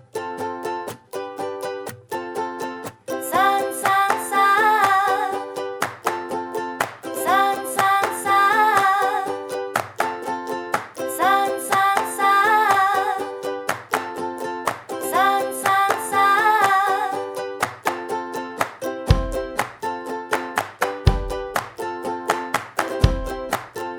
no Backing Vocals with Whistle Pop (2000s) 3:35 Buy £1.50